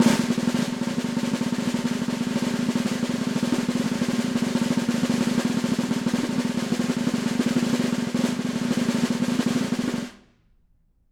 Snare2-rollSN_v3_rr1_Sum.wav